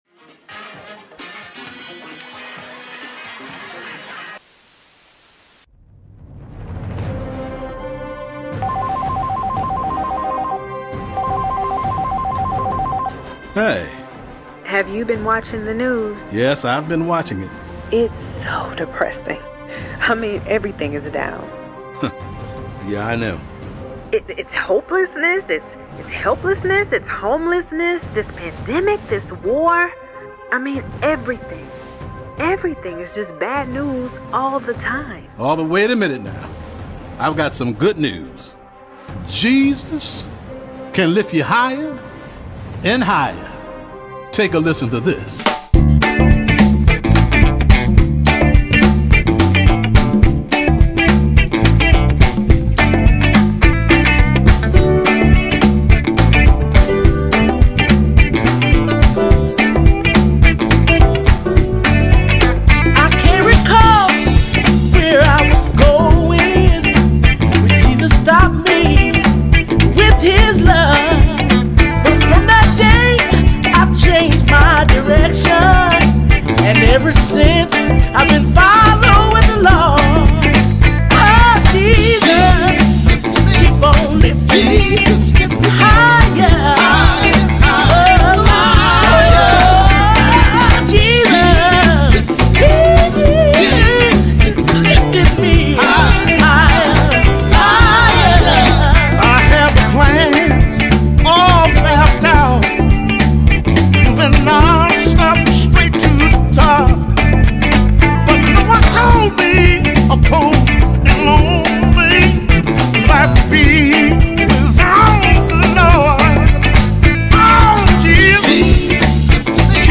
created for the purpose of soothing the weary soul.